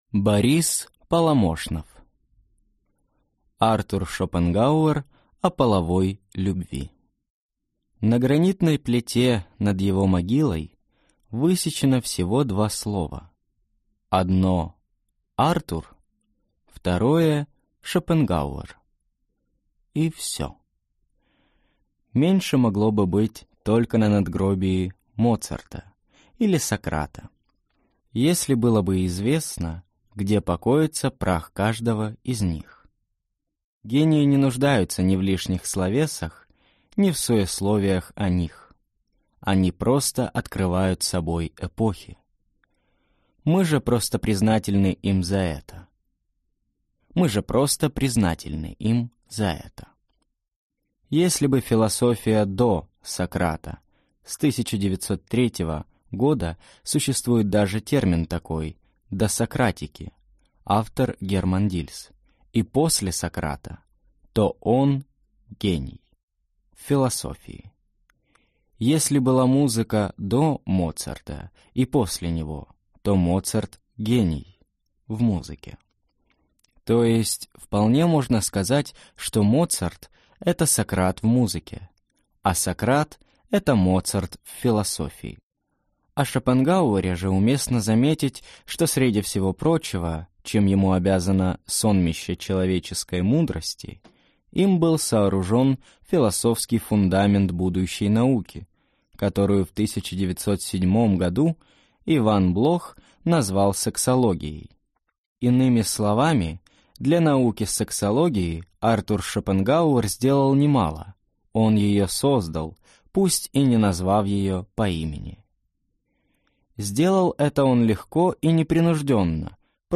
Аудиокнига Артур Шопенгауэр о половой любви | Библиотека аудиокниг